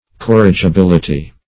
Search Result for " corrigibility" : The Collaborative International Dictionary of English v.0.48: Corrigibility \Cor`ri*gi*bil"i*ty\ (-j?-b?l"?-t?), n. Quality of being corrigible; capability of being corrected; corrigibleness.
corrigibility.mp3